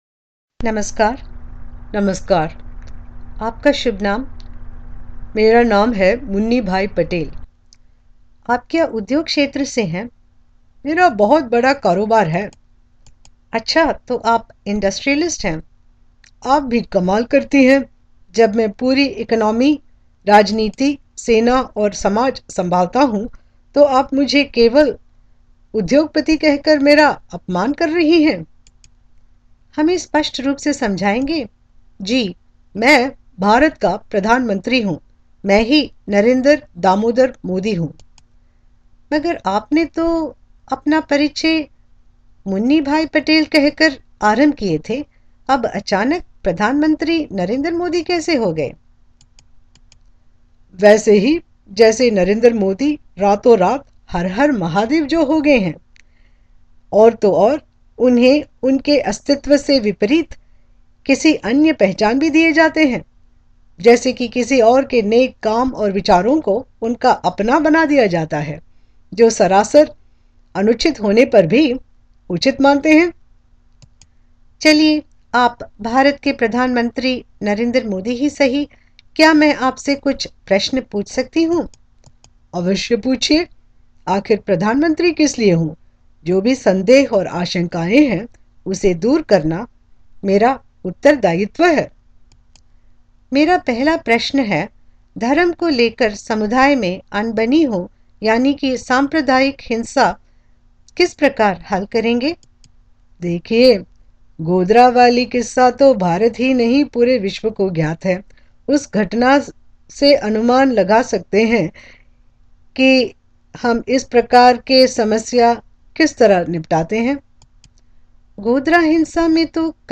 Interview with Indian Political Establishment – BJP Government led Indian Prime Minister Narendra Damodardas Modi in Hindi with simultaneous English translation is available in print under Audios category.
Interview-with-Indian-Prime-Minister-Narinder-Modi.mp3